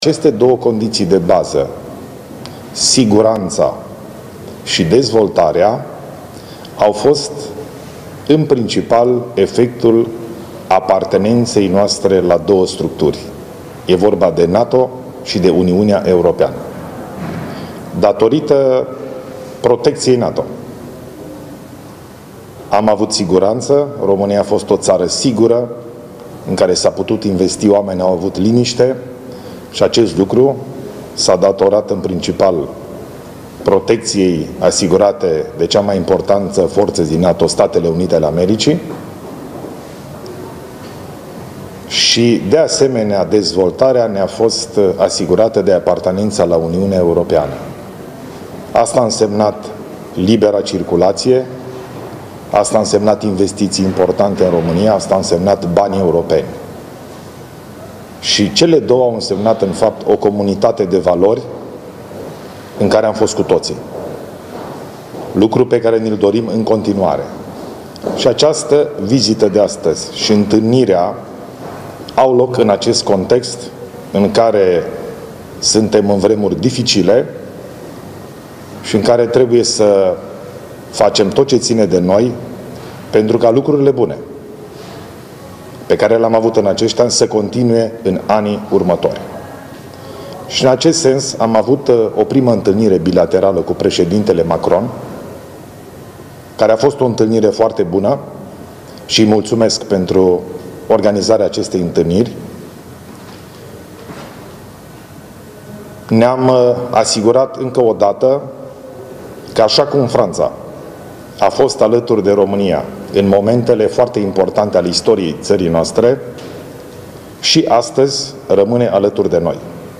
Președintele interimar al României – Ilie Bolojan – susține declarații de presă de la Paris, la finalul reuniunii de la Palatul Elysee. România a participat miercuri – 19 februarie 2025 – la o a doua reuniune a statelor europene în Franța.